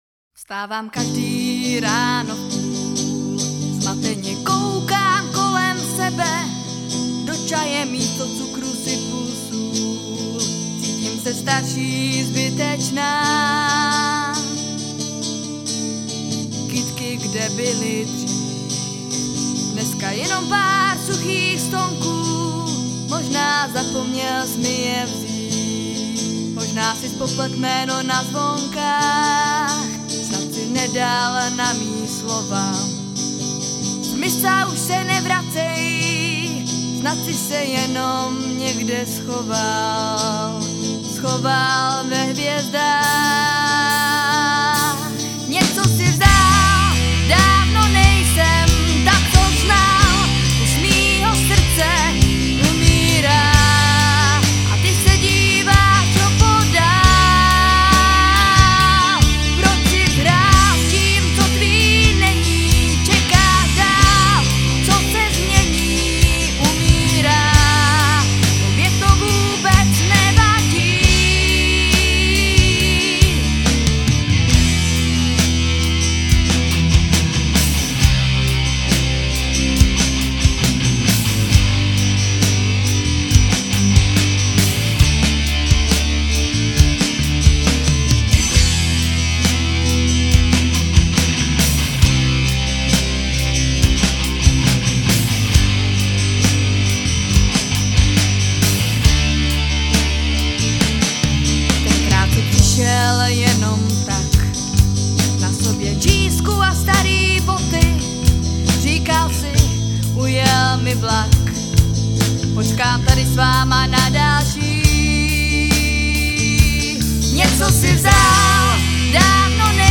rockovou kapelu